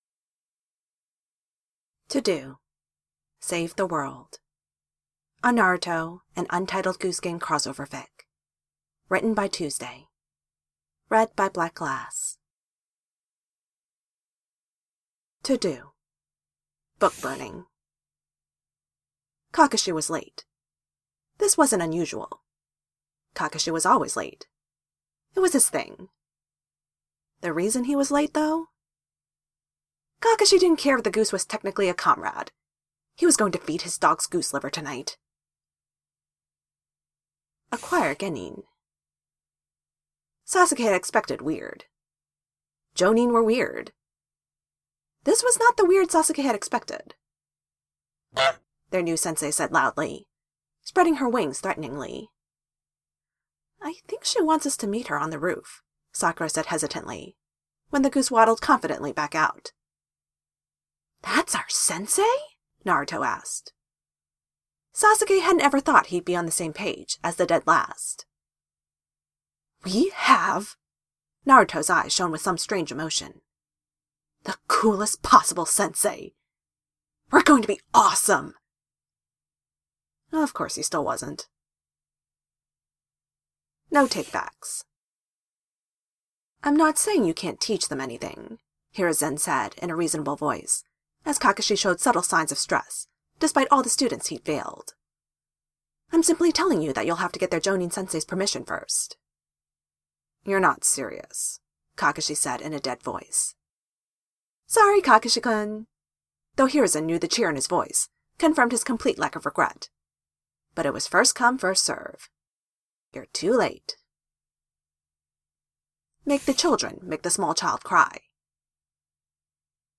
Length (without music): 8:02
(Also, yes, I did pull the honk and cross out sound effects from the game. :P)
to do save the world (no music).mp3